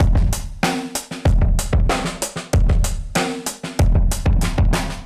Index of /musicradar/dusty-funk-samples/Beats/95bpm/Alt Sound
DF_BeatB[dustier]_95-01.wav